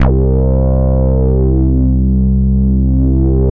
Index of /90_sSampleCDs/Trance_Explosion_Vol1/Instrument Multi-samples/Wasp Bass 2
C2_WaspBass2.wav